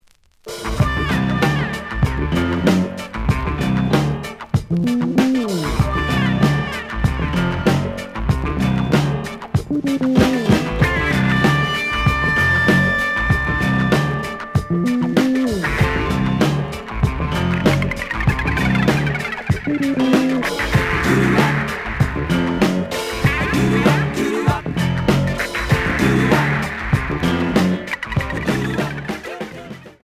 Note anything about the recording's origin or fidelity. The audio sample is recorded from the actual item. Slight edge warp. But doesn't affect playing.